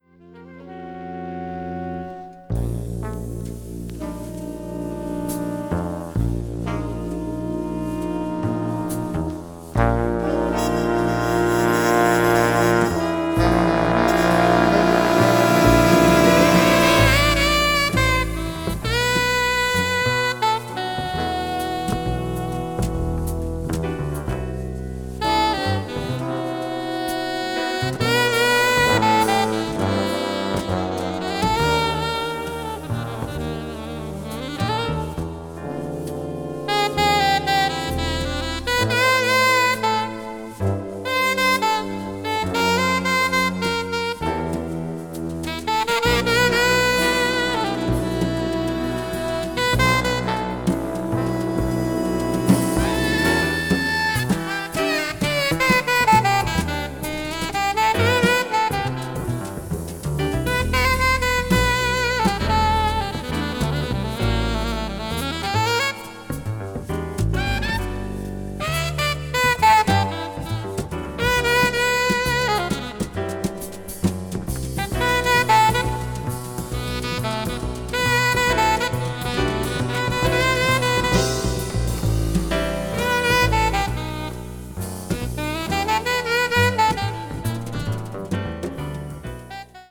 media : EX/EX(some slightly noises.)
pianist
saxophonist